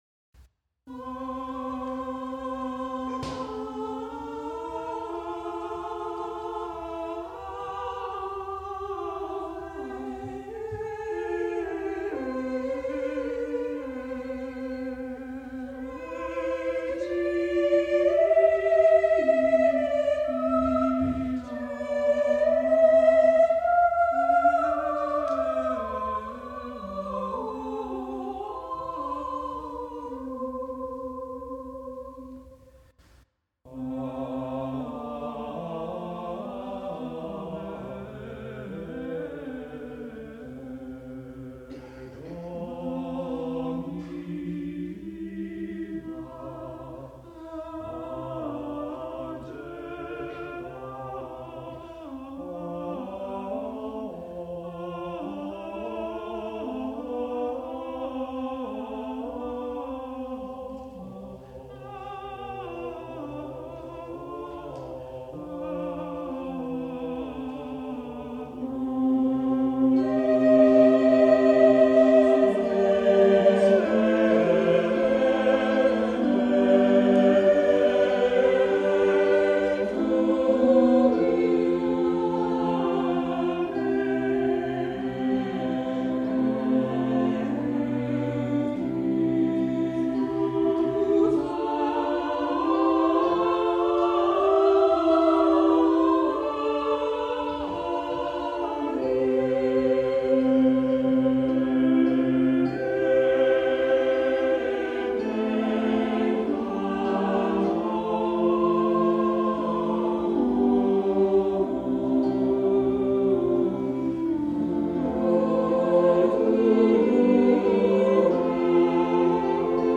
In July 1474 Dufay’s Will requested that the Choir sing his motet, “Ave Regina coelorum” at his death bed.